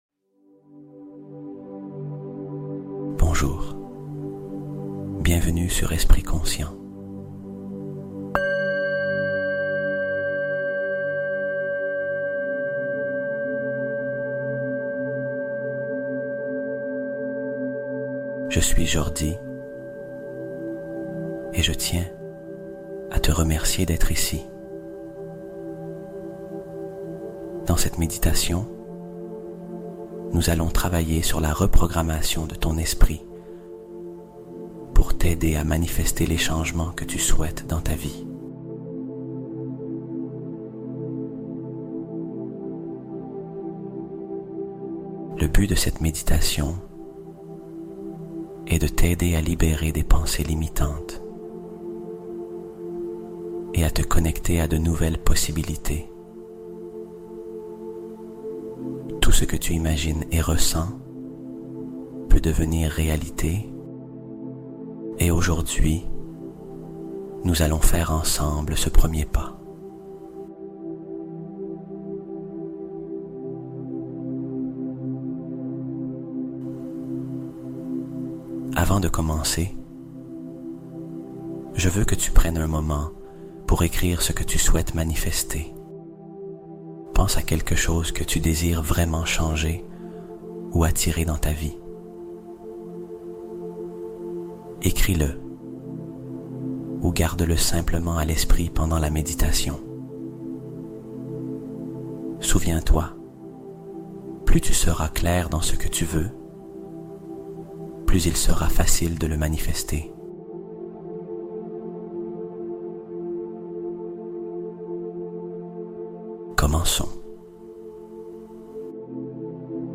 Réceptivité : Méditation pour attirer des opportunités en une séance